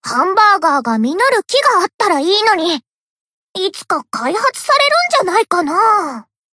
贡献 ） 分类:蔚蓝档案语音 协议:Copyright 您不可以覆盖此文件。
BA_V_Izumi_Cafe_Monolog_1.ogg